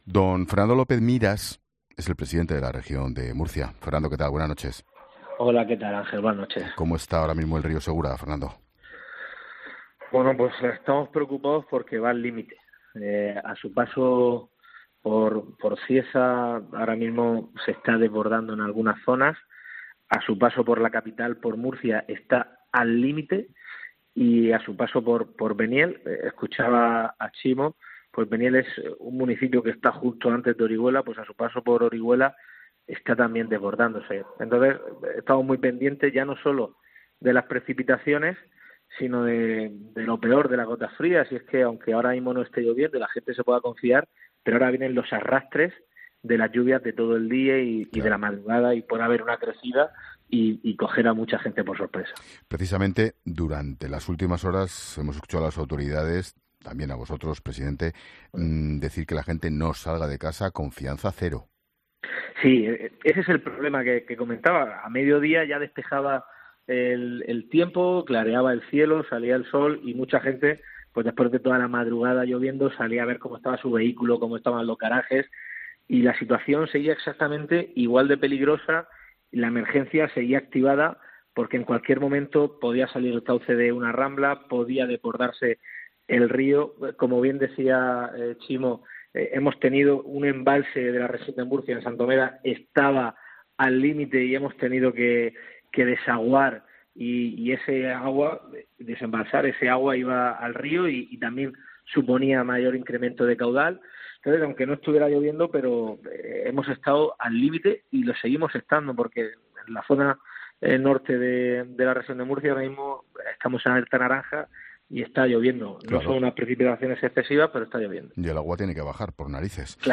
El presidente murciano ha estado en 'La Linterna' para hacer balance de los daños de la gota fría en la región